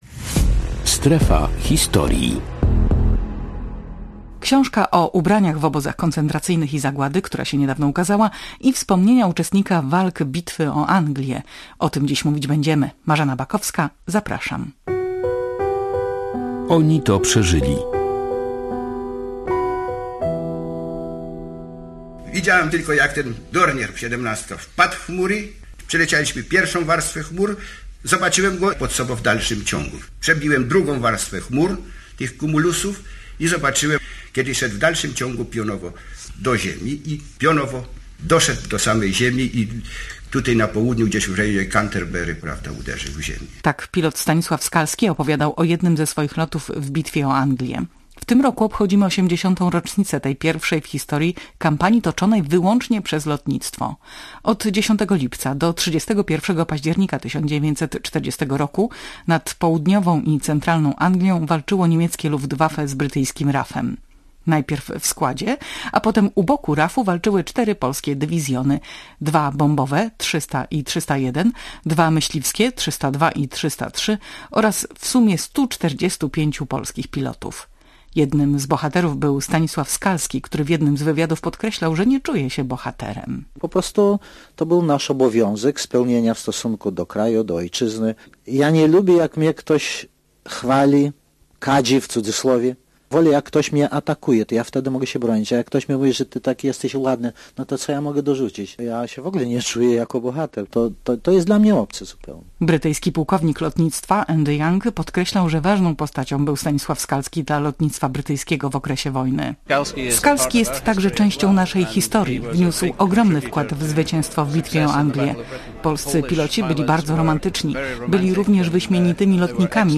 w audycji przypominamy 80. rocznicę Bitwy o Anglię we wspomnieniach bohatera tych walk Stanisława Skalskiego.